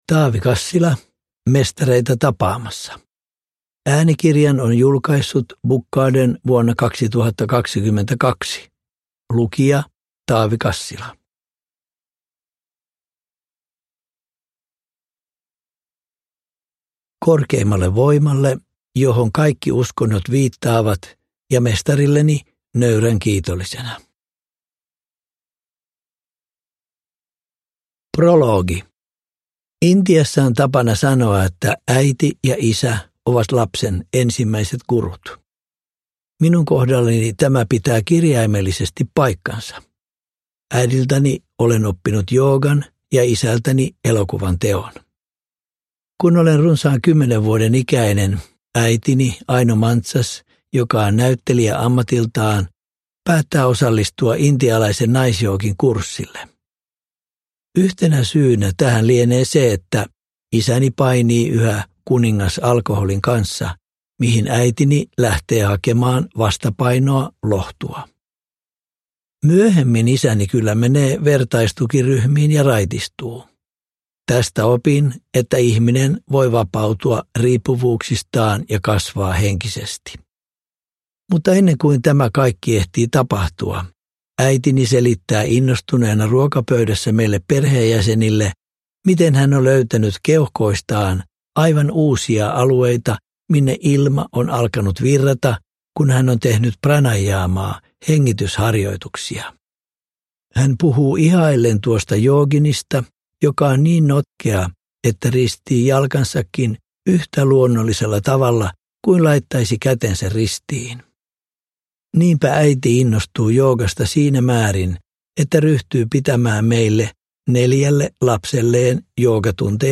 Mestareita tapaamassa – Ljudbok